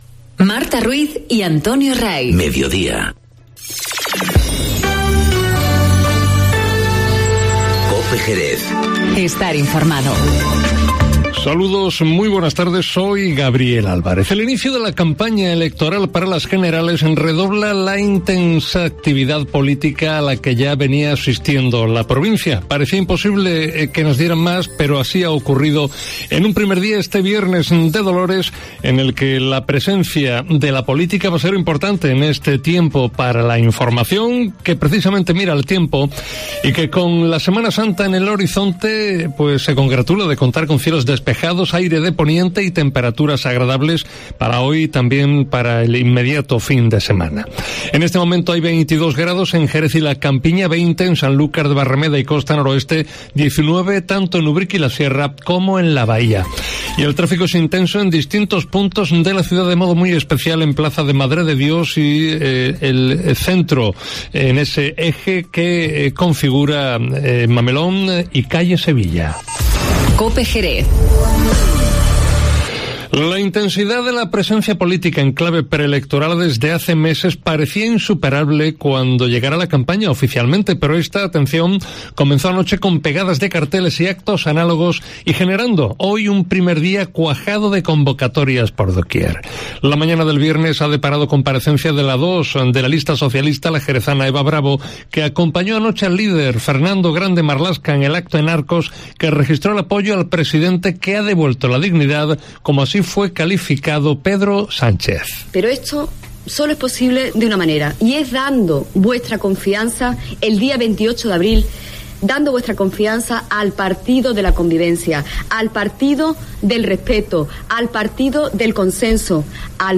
Informativo Mediodía COPE Jerez (12/04/19)